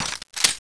glock_clipin.wav